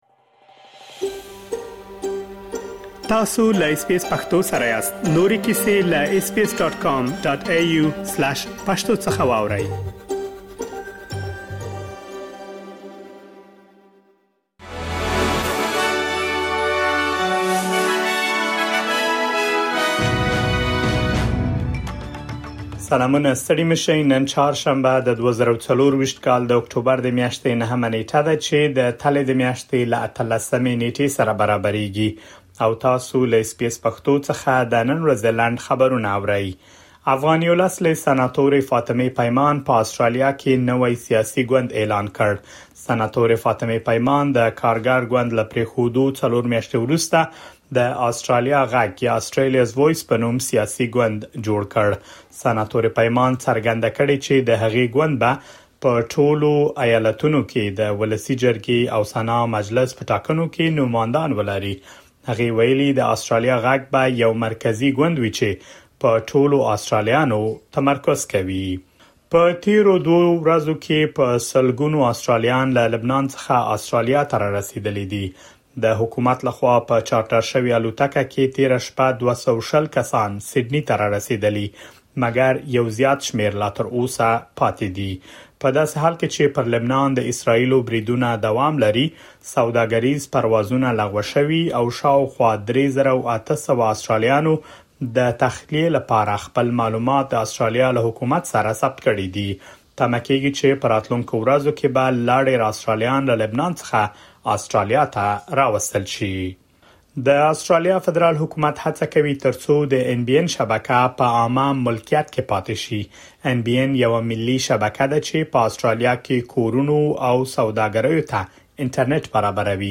د اس بي اس پښتو د نن ورځې لنډ خبرونه|۹ اکټوبر ۲۰۲۴